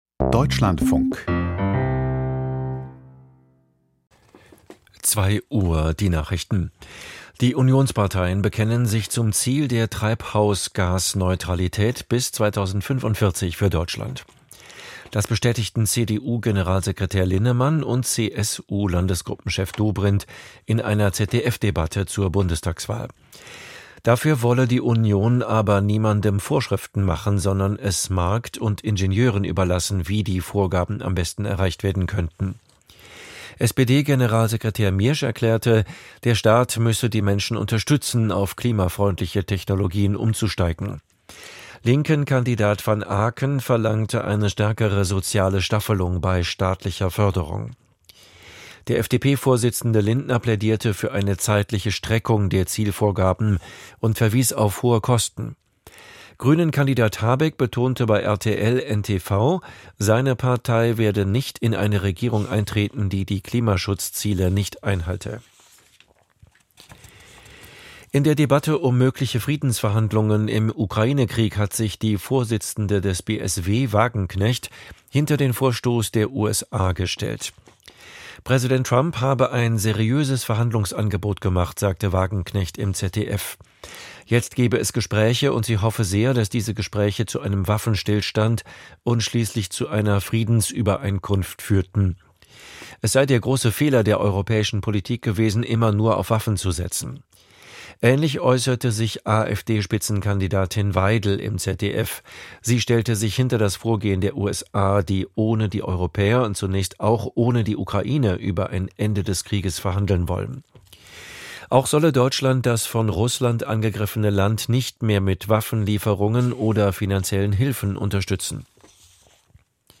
Die Nachrichten